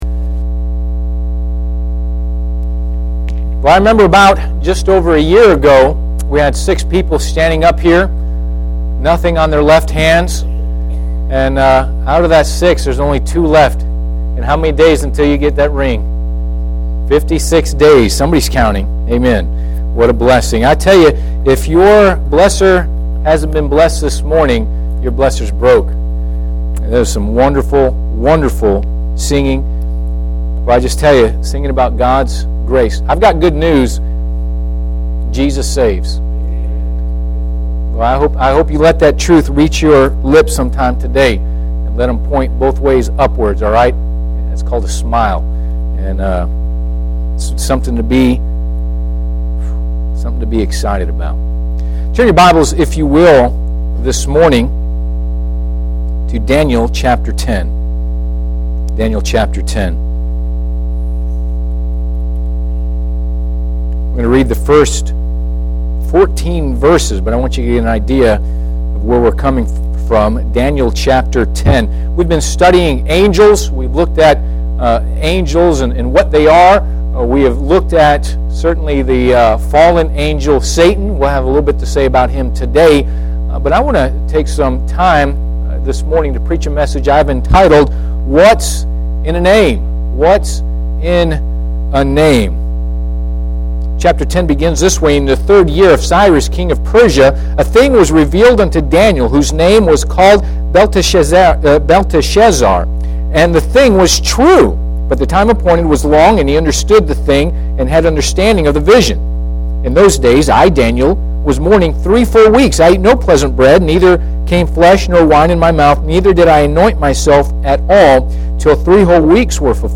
Passage: Daniel 10:1-14 Service Type: Sunday AM Bible Text